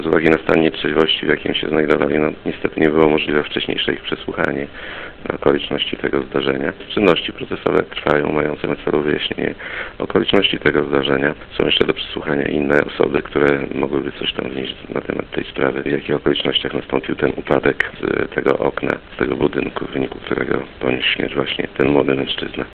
Ustalane są przede wszystkim okoliczności tej tragedii- mówi w rozmowie z Radiem 5 prokurator Jan Mikucki szef Prokuratury Rejonowej w Ełku.